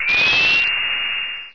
defabSpinup.ogg